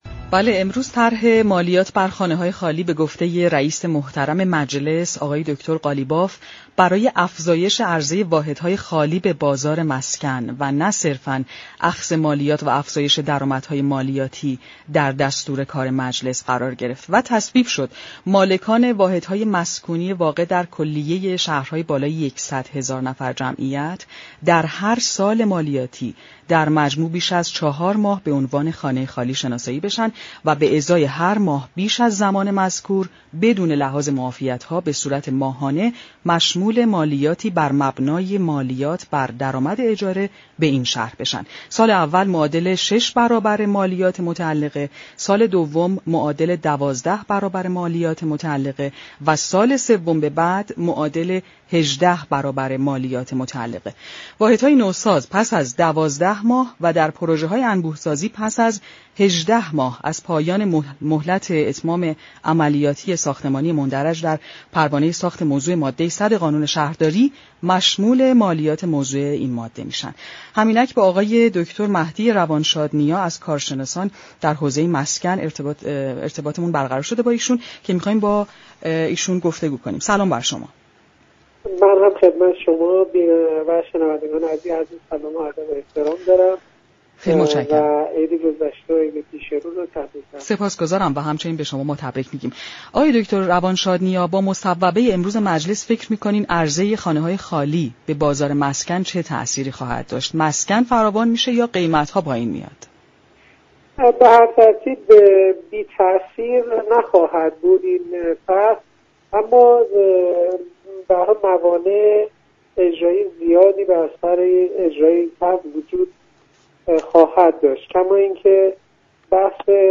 در گفتگو با بازار تهران